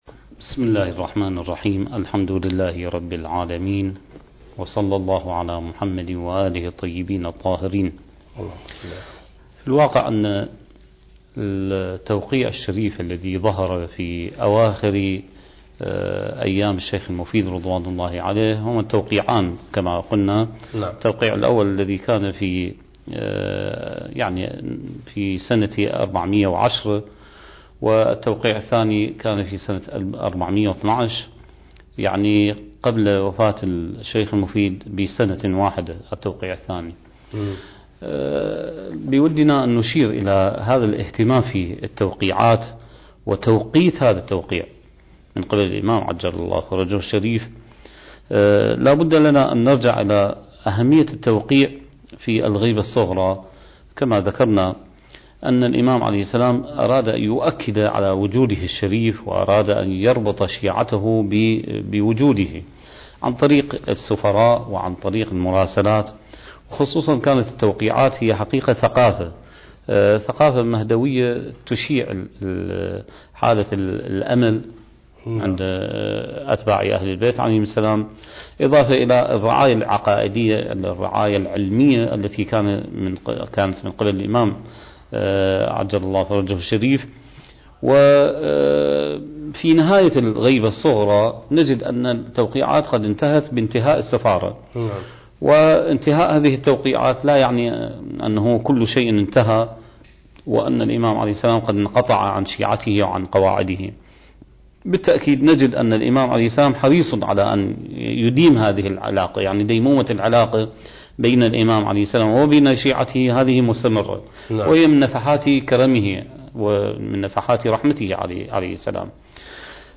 سلسلة محاضرات: بداية الغيبة الصغرى (8) برنامج المهدي وعد الله انتاج: قناة كربلاء الفضائية